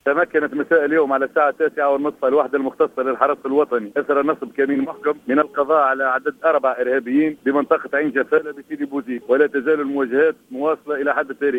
في اتصال هاتفي